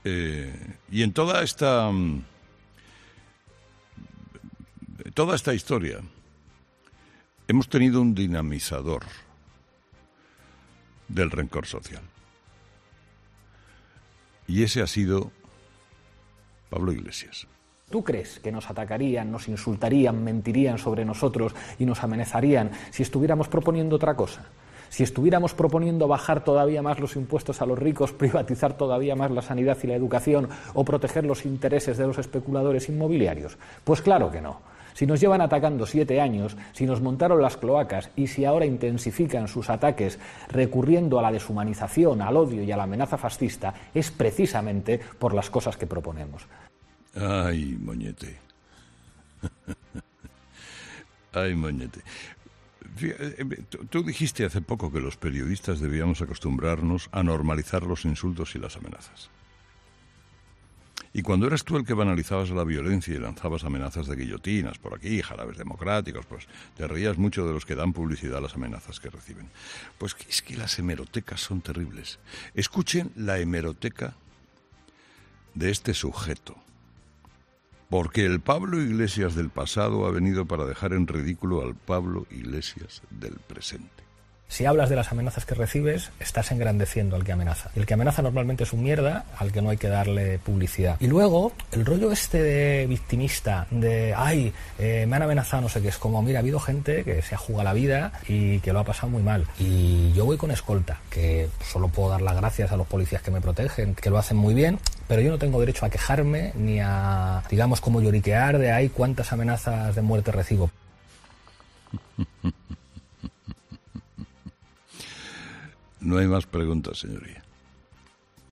El presentador de 'Herrera en COPE' ha recuperado una de las frases más controvertidas del candidato de Unidas Podemos con la cual ha desmontado uno de sus argumentos
"Ay moñete... Tú dijiste que los periodistas debíamos acostumbrarnos a normalizar los insultos y las amenazas, y cuando eras tú quien banalizada la violencia y lanzabas mensajes de jarabe democrático, te reías mucho de los que dan publicidad a las amenazas que reciben. Las hemerotecas son terribles...", ha dicho Herrera, y acto seguido ha recuperado una de las frases que hace años dijo Iglesias sobre las amenazas a los políticos.